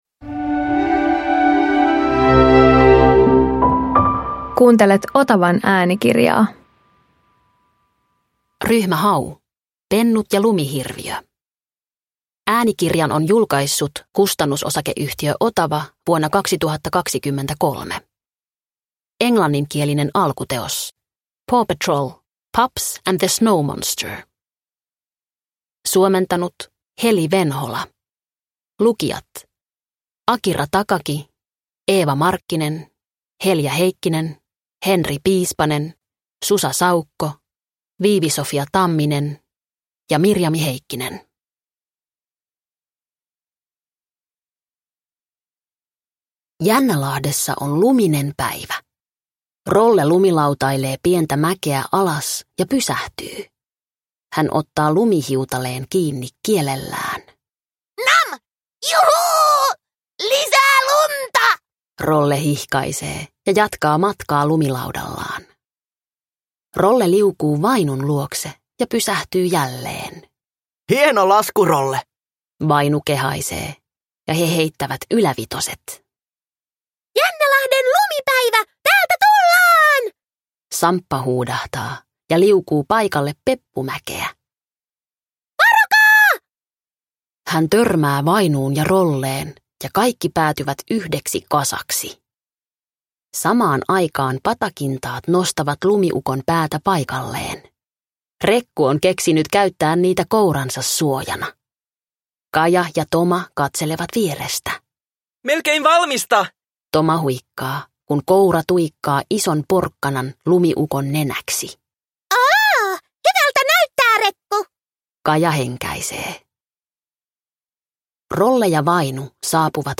Ryhmä Hau Pennut ja lumihirviö – Ljudbok – Laddas ner